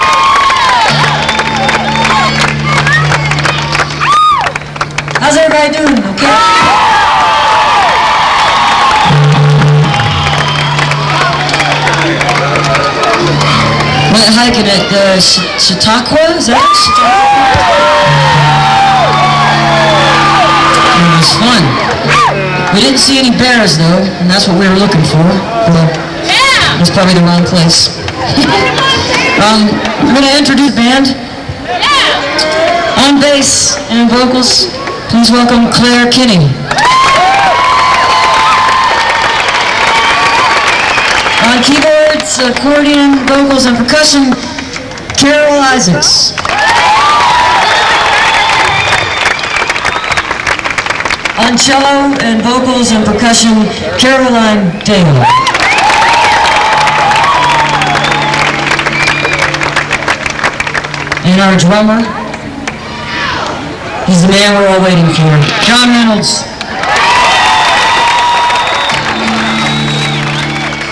lifeblood: bootlegs: 1999-08-29: fiddlers green amphitheater - denver, colorado (lilith fair)
07. band introductions (1:10)